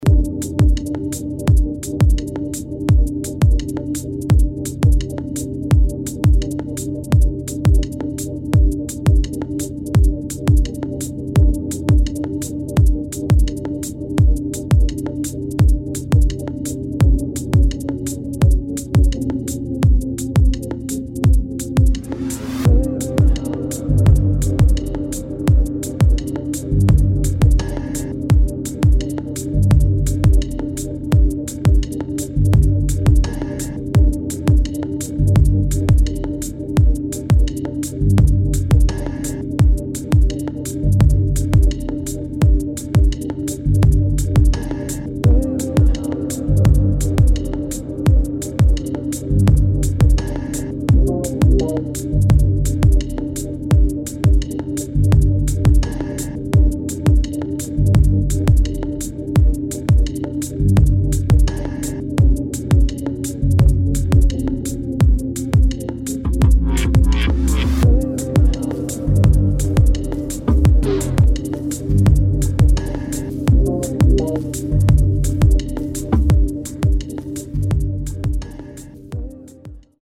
[ DRUM'N'BASS | BASS | EXPERIMENTAL ]